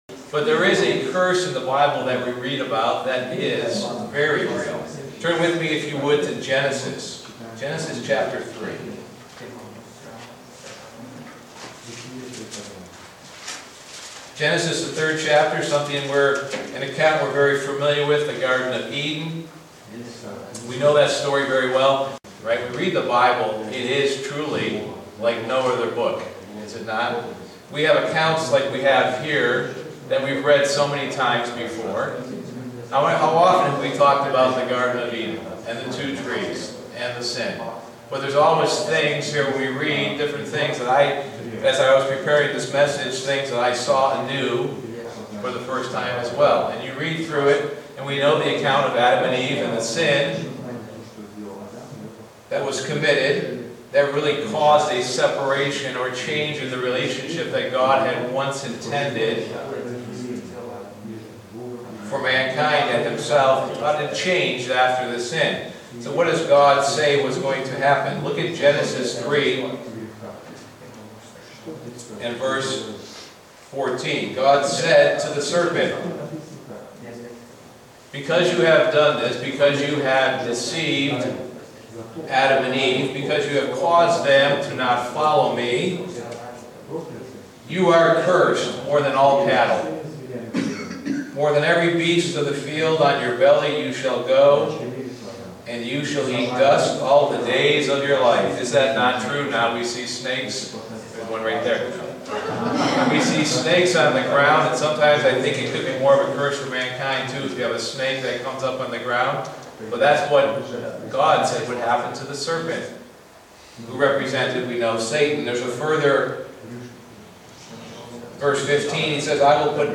Print Life is temporary and the curse we live under is also temporary. sermon Studying the bible?